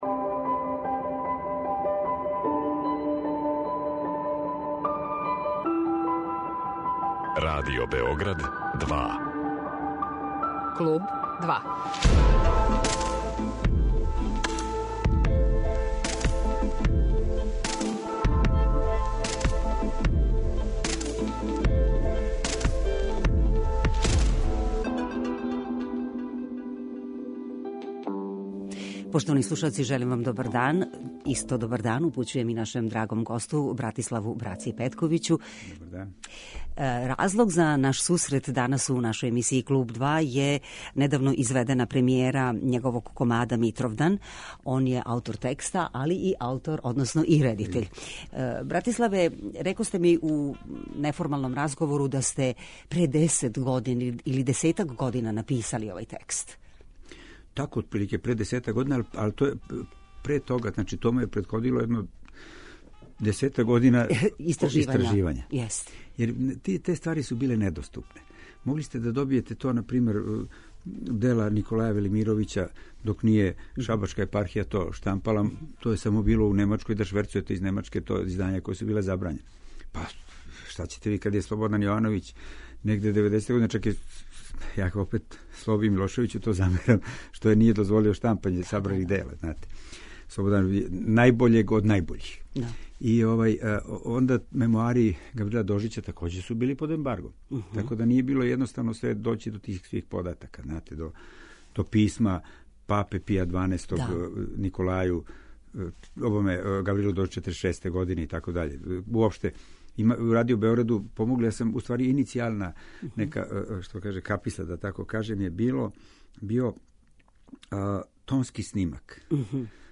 Гост Братислав Петковић
Гост емисије Kлуб 2 је Братислав Браца Петковић, редитељ, аутор драме Митров дан, која се са великим успехом приказује у Театру опери Мадленијанум. Због тога је и уприличена ова реприза разговора.